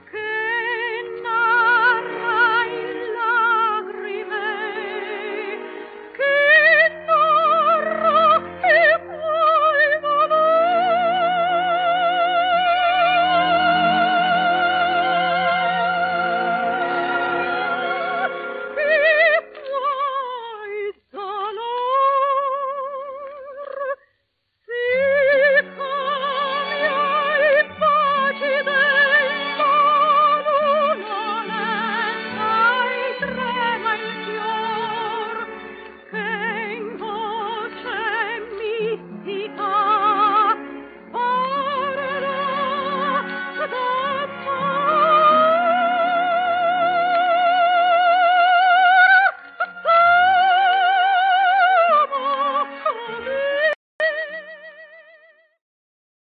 Lyric Soprano